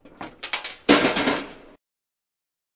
废弃的工厂金属后世界末日的回声" 爆炸3
描述：记录在都柏林的一家废弃工厂。
Tag: 工业 工厂 金属 崩溃 噪声